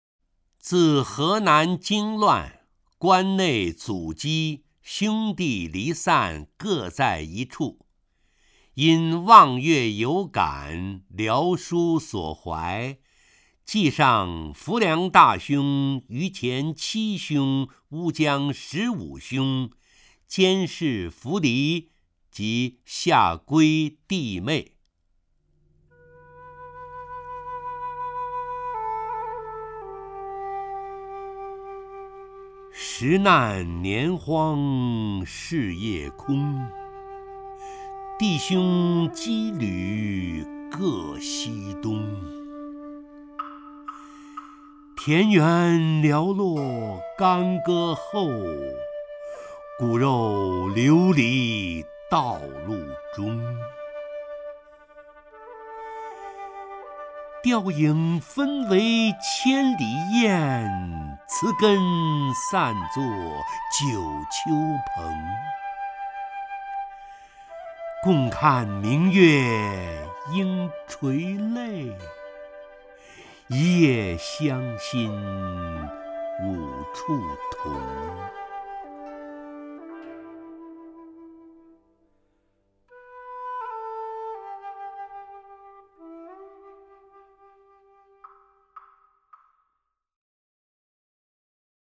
陈醇朗诵：《望月有感》(（唐）白居易)
名家朗诵欣赏 陈醇 目录